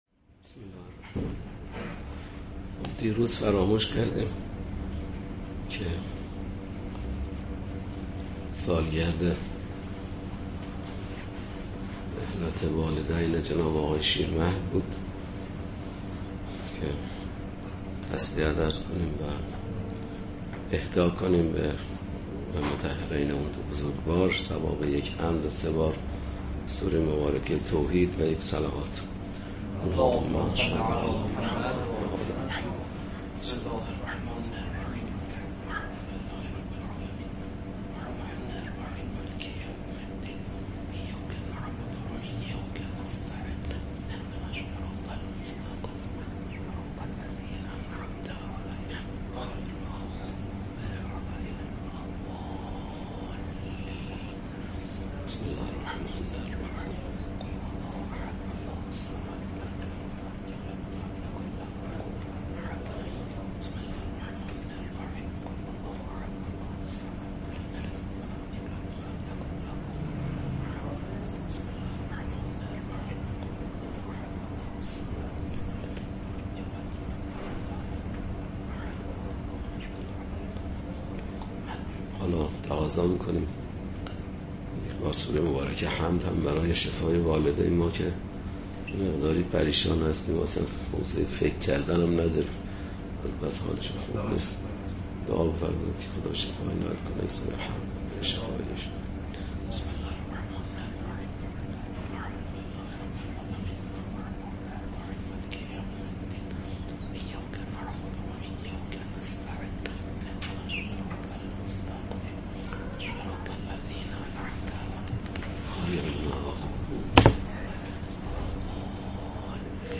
لازم به ذکر است: متن ذیل پیاده شده از صوت درس می‌باشد و هیچگونه ویرایشی روی آن اعمال نشده است.